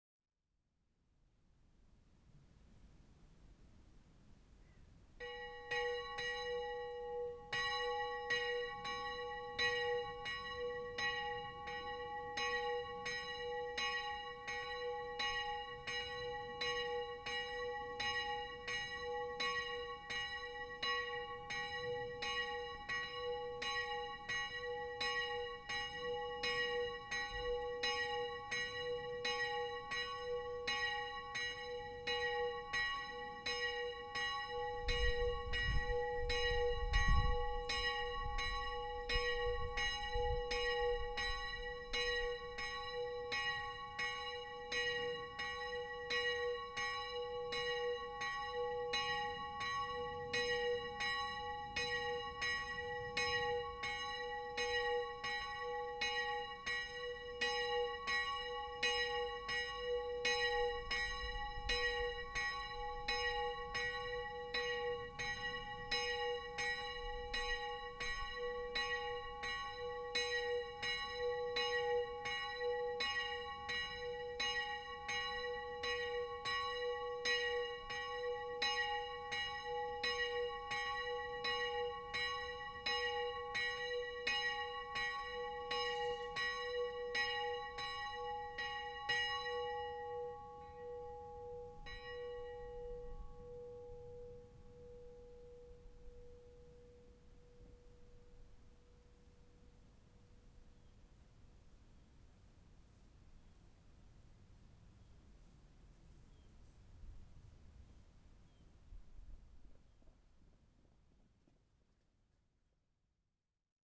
Auch wenn die Kirche heute nicht geöffnet ist, soll die Glocke der Kapelle läuten.
Glocke St. Mariä Vermählung